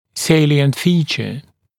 [‘seɪlɪənt ‘fiːʧə][‘сэйлиэнт ‘фи:чэ]характерная черта, присущая черта